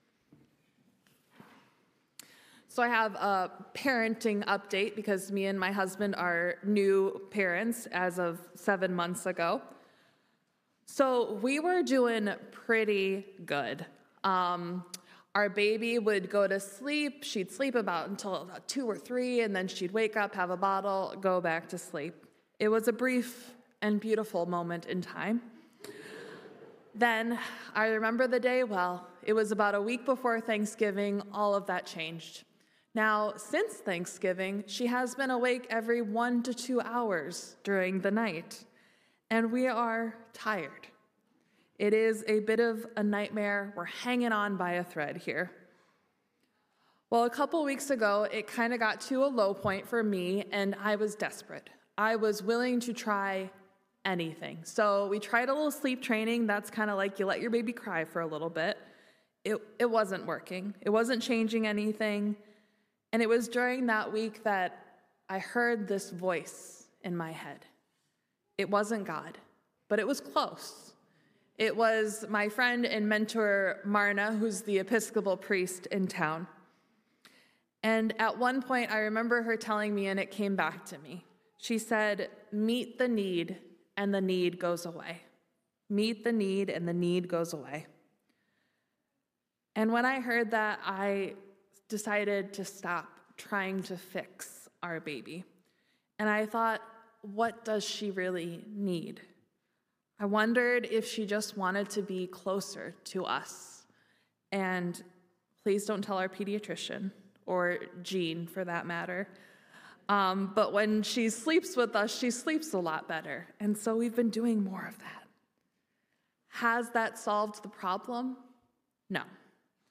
Sermons | Messiah Lutheran Church, Marquette